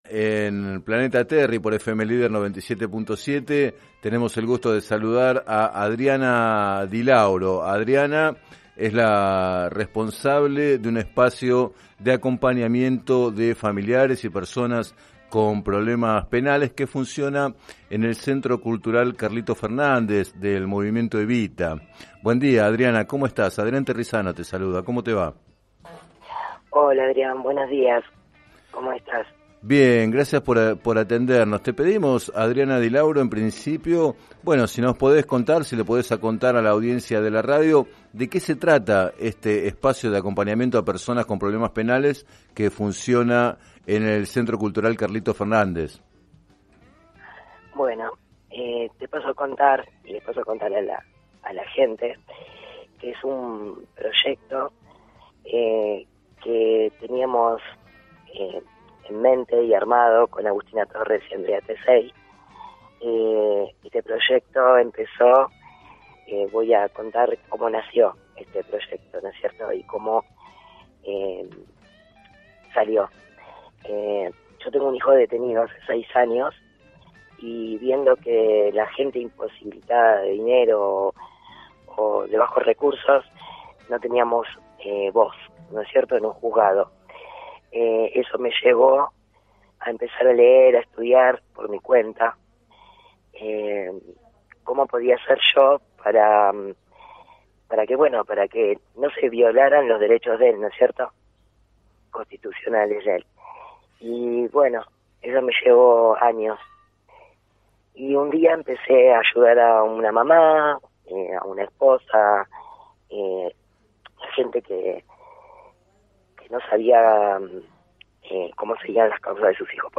En declaraciones al programa “Planeta Terri” de FM Líder 97.7